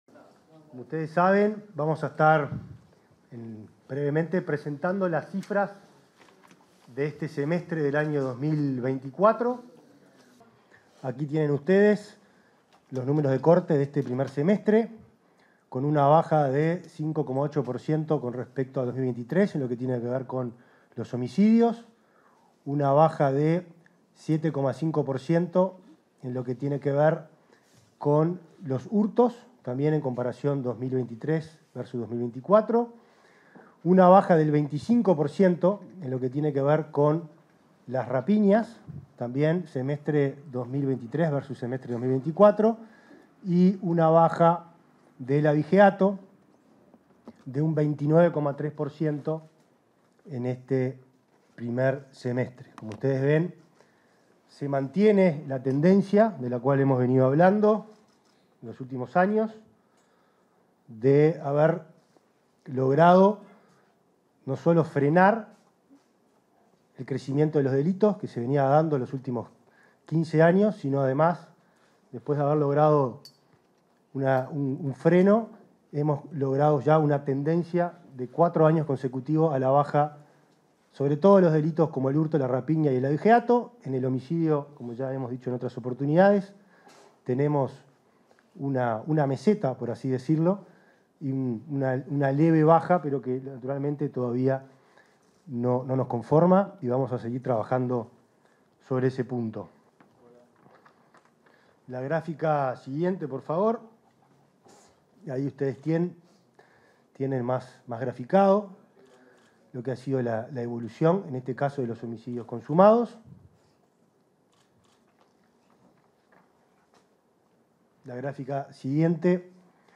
Palabras del ministro del Interior, Nicolás Martinelli
Conferencia sobre presentación del Ministerio del Interior